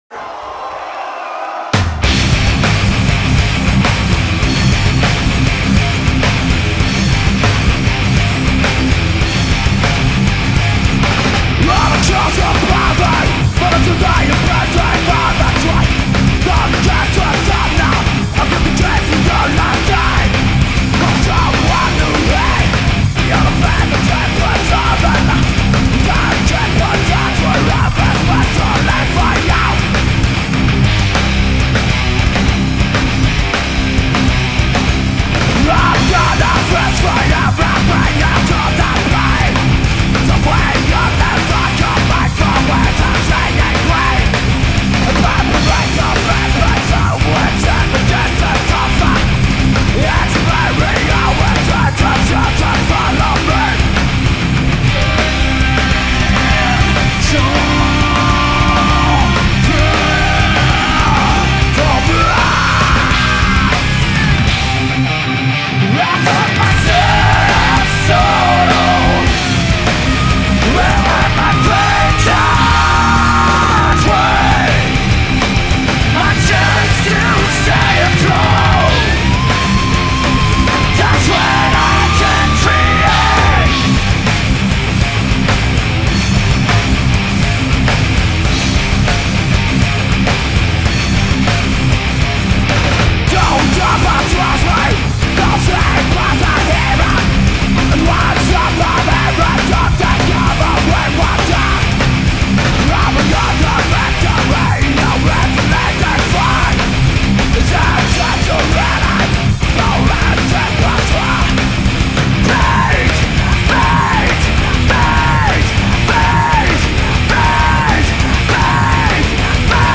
Les métalleux français
Celui-ci a été enregistré live le 30 mars dernier à Angers.